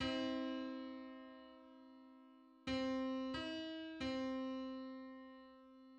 File:Six-hundred-twenty-seventh harmonic on C.mid - Wikimedia Commons
Public domain Public domain false false This media depicts a musical interval outside of a specific musical context.
Six-hundred-twenty-seventh_harmonic_on_C.mid.mp3